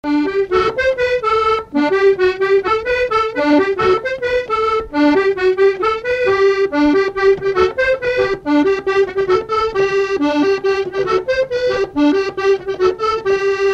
Mémoires et Patrimoines vivants - RaddO est une base de données d'archives iconographiques et sonores.
danse-jeu : guimbarde
Pièce musicale inédite